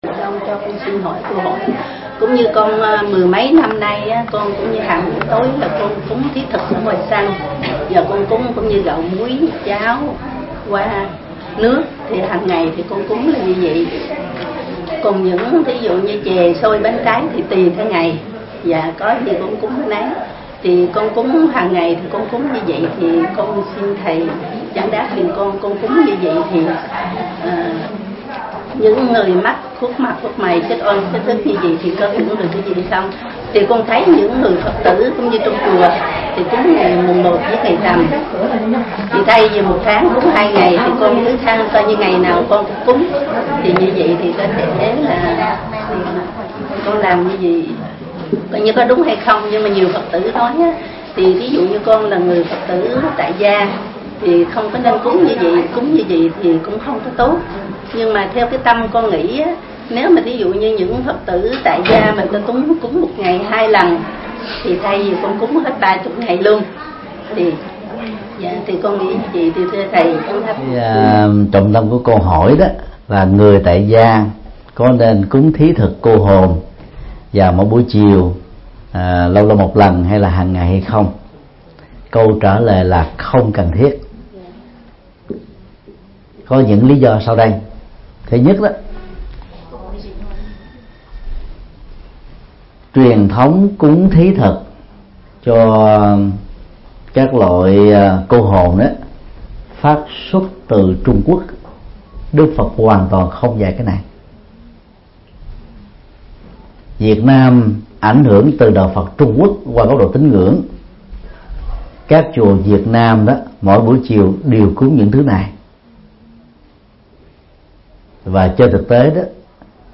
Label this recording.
Vấn đáp: Cúng thí thực cô hồn